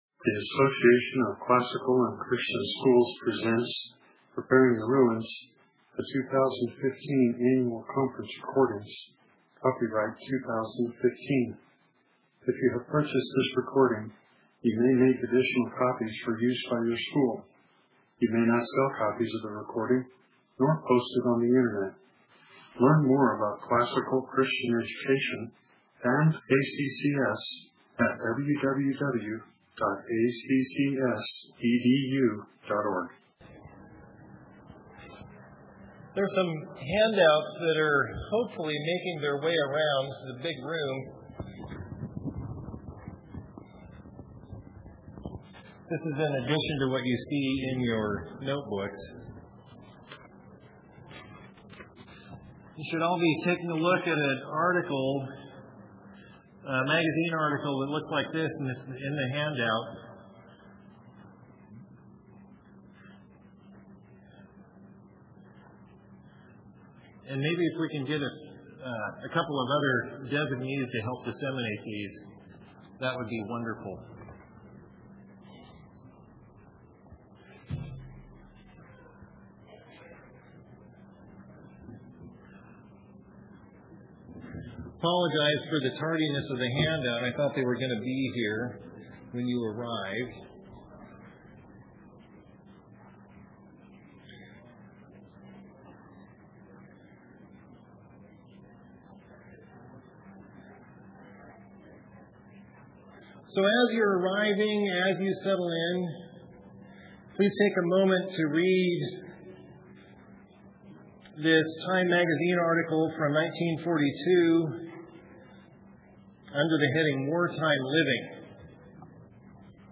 2015 Workshop Talk | 1:02:53 | All Grade Levels, General Classroom